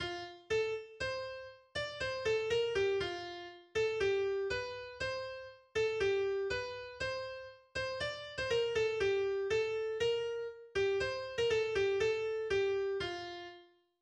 Weihnachtslied